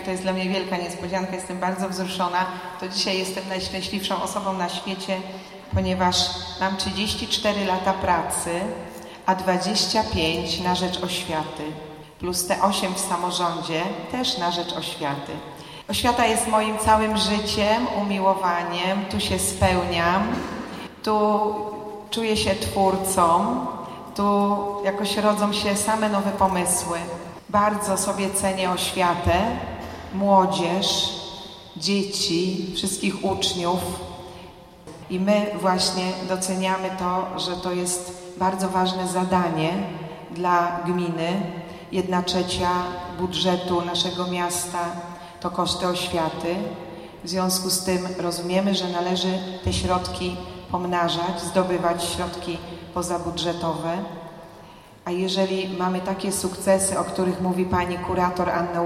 Wzruszenia po otrzymaniu nagrody nie kryła odznaczona wiceburmistrz Aleksandra Nowakowska, która jak powiedziała oświata jest dla niej bardzo ważna.